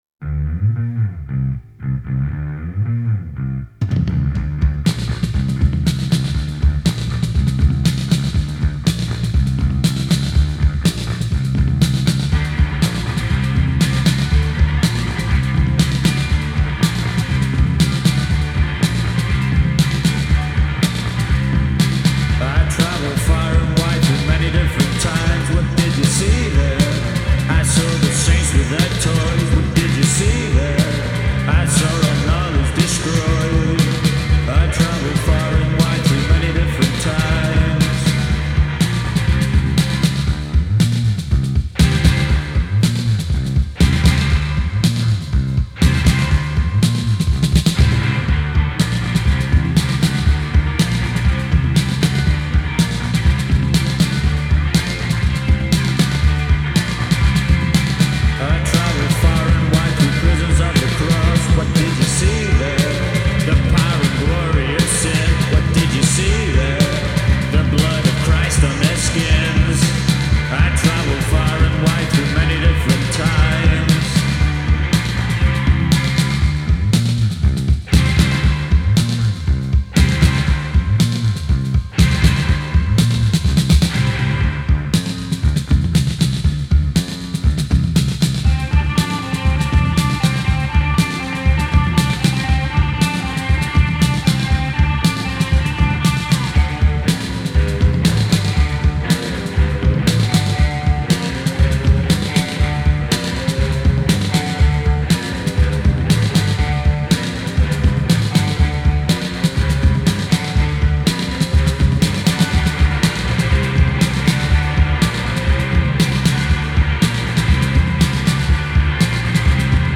Punk Rock Post rock